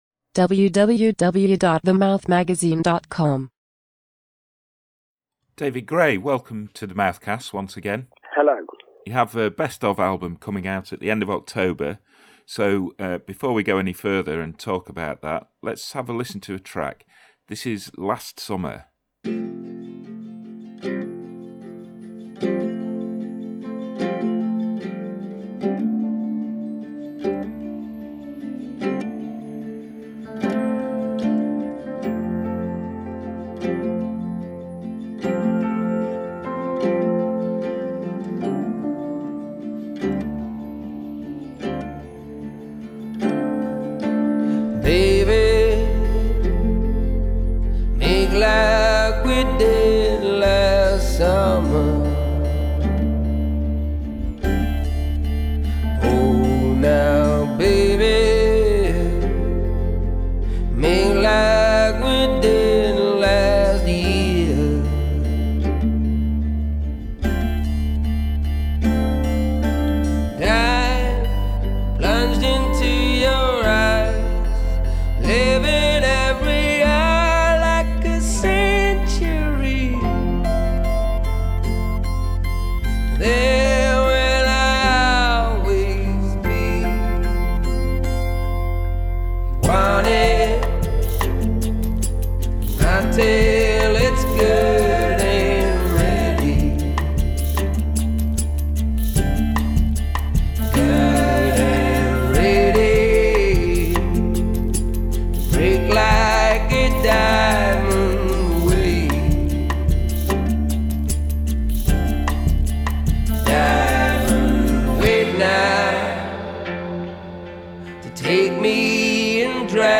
ON THE VERGE OF RELEASING A NEW BEST OF ALBUM, WHICH ROUNDS UP TWO DISCS’ WORTH OF HIGHLIGHTS FROM HIS TWO DECADE CAREER, SONGWRITER DAVID GRAY TALKS TO THE MOUTH MAGAZINE ABOUT HIS BODY OF WORK… HE REFLECTS ON THE SUCCESS OF BREAKTHROUGH ALBUM WHITE LADDER, AND CONSIDERS HOW HIS WRITING PROCESS HAS CHANGED IN THE YEARS BETWEEN IT AND MOST RECENT STUDIO OFFERING MUTINEERS…